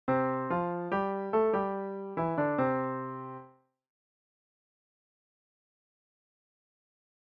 one a mainly descending idea, the other arch-shaped